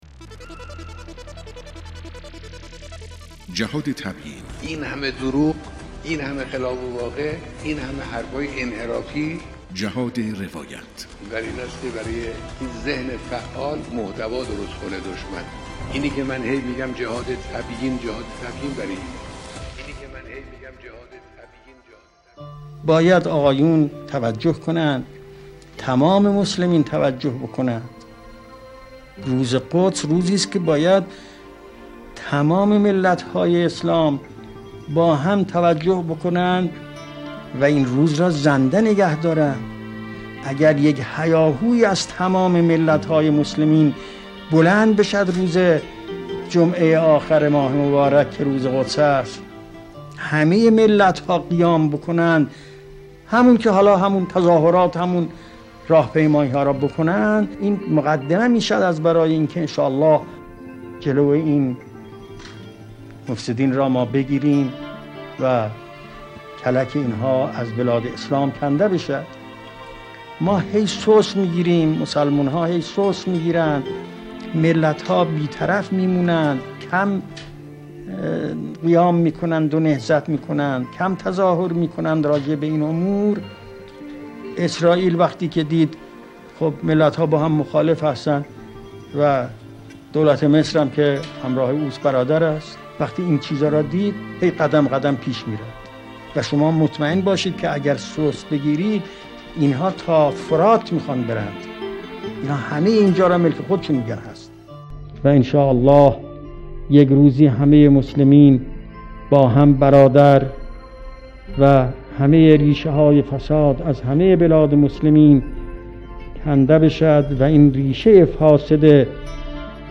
رادیو صدای انقلاب 1736 | بیانات حکیمانه امام خمینی(ره)